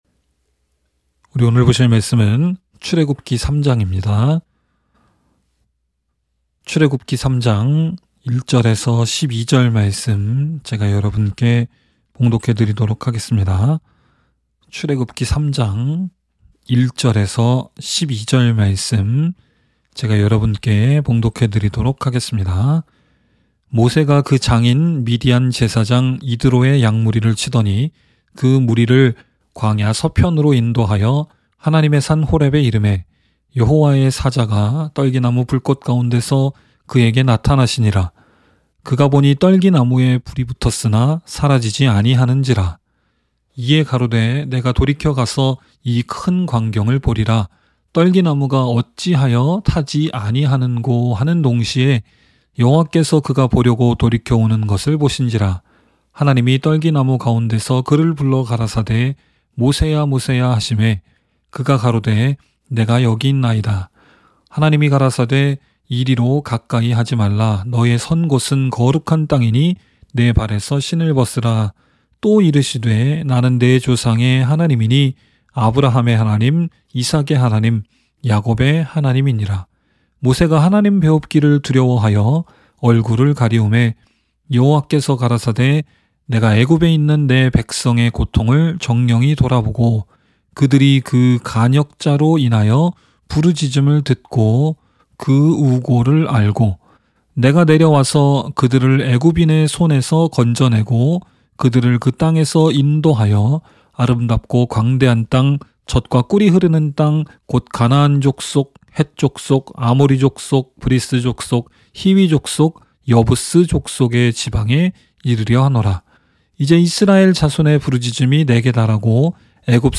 by admin-new | Mar 7, 2022 | 설교 | 0 comments